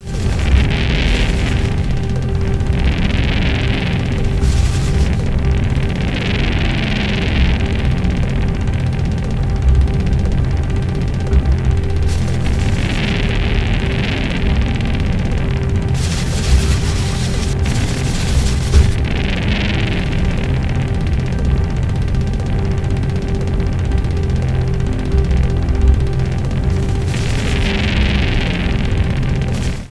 live-mitschnitte aus den inatallationen in der bramfelder straße, 8 bit 22 khz 30 sec.: